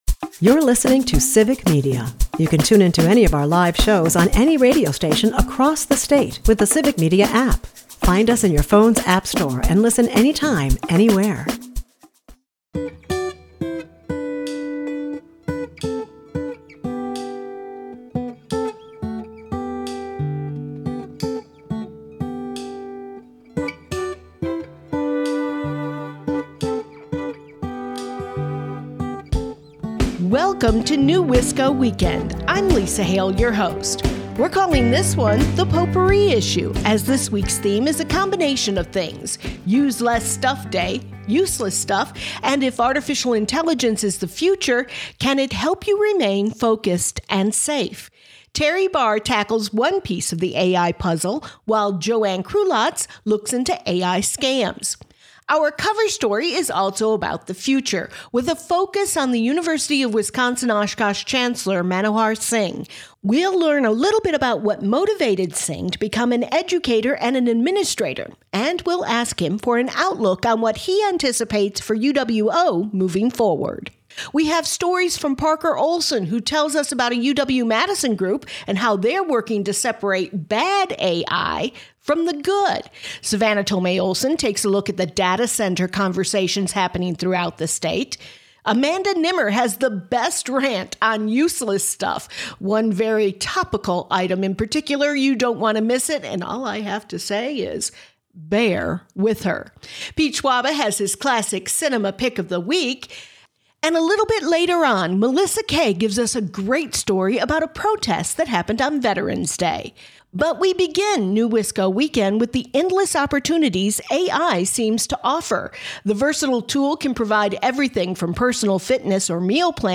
The Cover Story is a talk with UW Oshkosh Chancellor Manohar Singh.